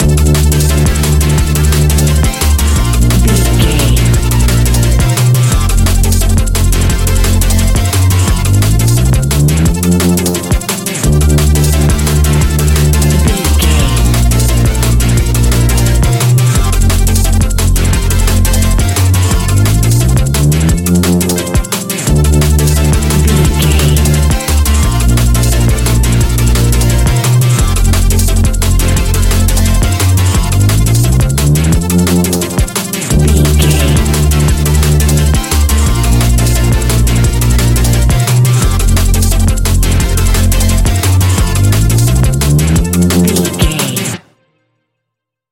Ionian/Major
A♯
electronic
techno
trance
synths
synthwave